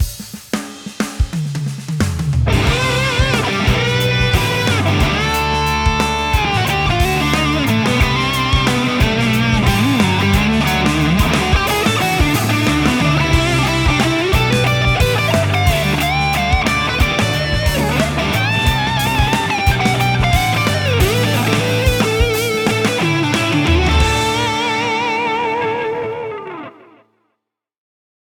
Demo Song
ギターは4つの音色を使っています。あとはドラムとベースですね。
クリーン、クランチ、トレモロ、そしてリードです。
クランチはシンプルに白玉っぽく弾いています。濁りやすいので、コードは厚くせず、パワーコードを基準に弾いています。
反対にトレモロはしっかりコードを弾いてコード感を出しています。クリーントーンはキメとなるような場所ですね。